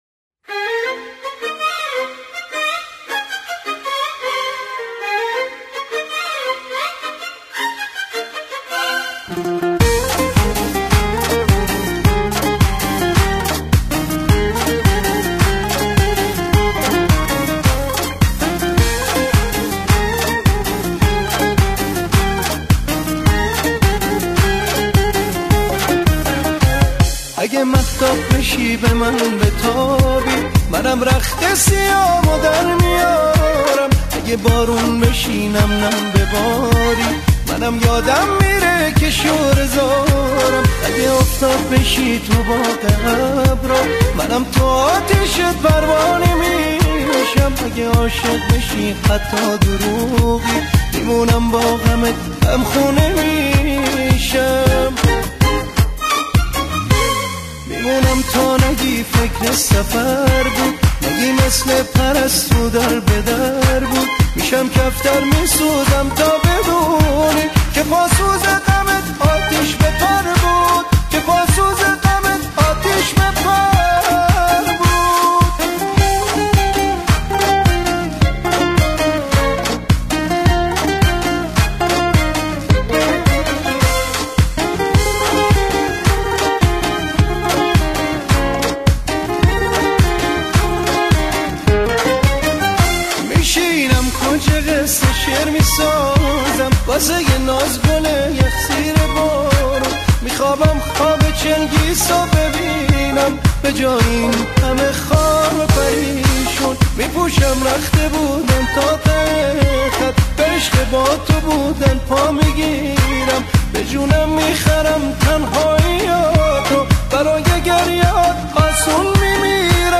اهنگ شاد ایرانی
اهنگ قدیمی خاطره انگیز